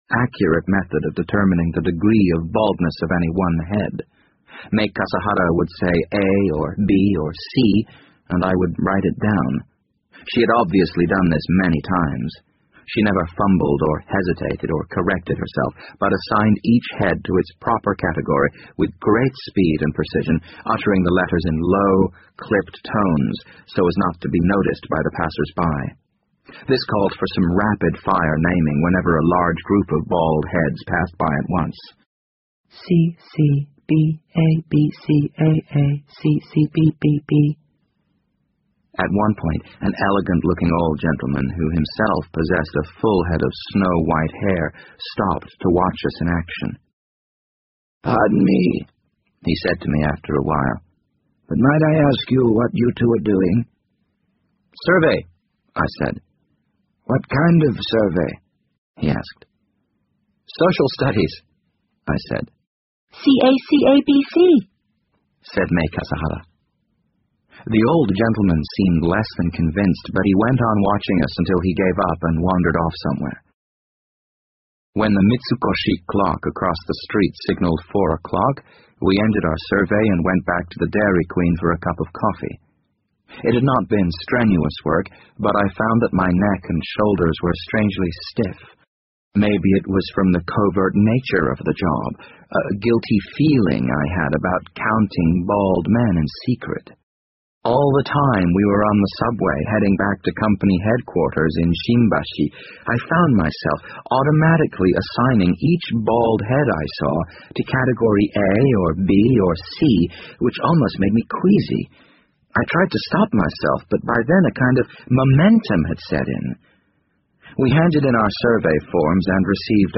BBC英文广播剧在线听 The Wind Up Bird 56 听力文件下载—在线英语听力室